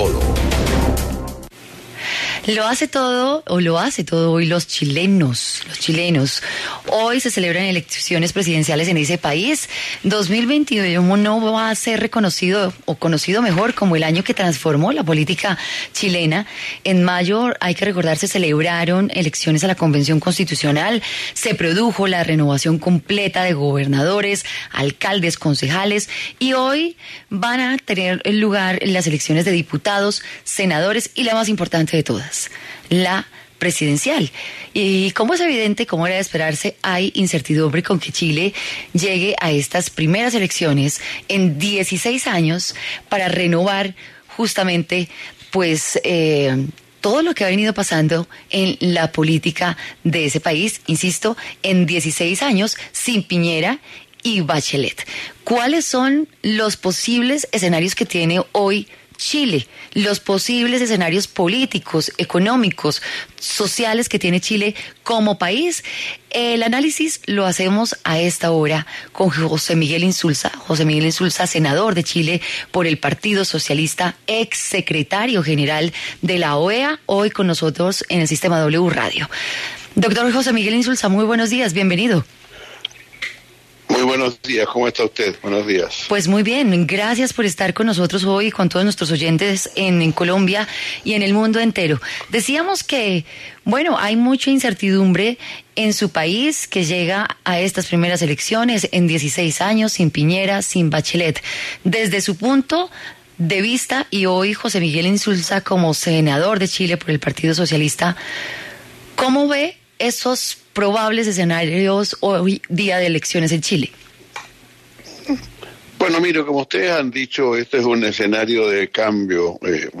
W Fin de Semana conversó con José Miguel Insulza, senador por el Partido Socialista de Chile, sobre estos comicios electores en los que 15 millones de ciudadanos están llamados a votar.